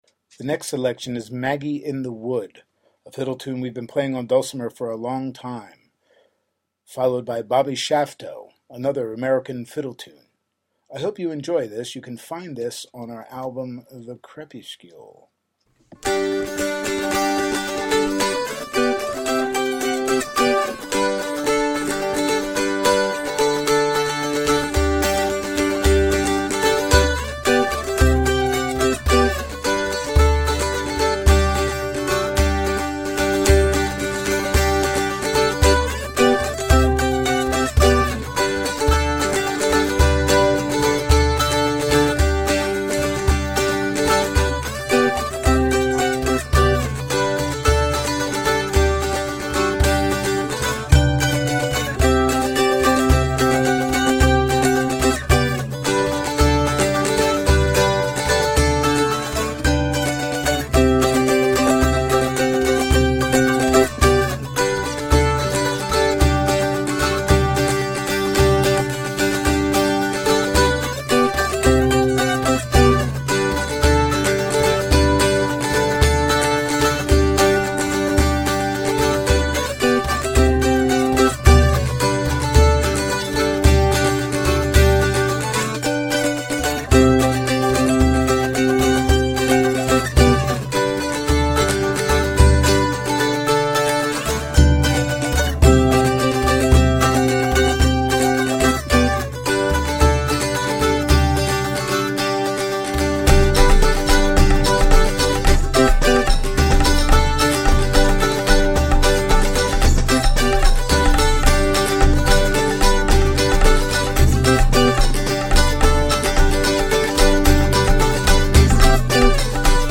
Country
Folk
World music